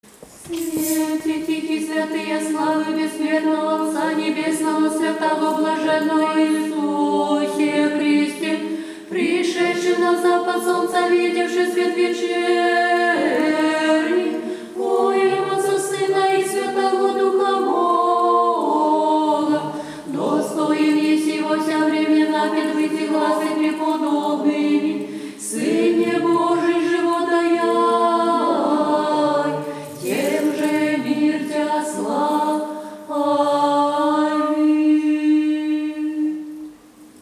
Свете Тихий – православное песнопение, прославляющее Спасителя Господа Иисуса Христа, явившего человечеству Тихий Свет Божественной Славы Своего Небесного Отца.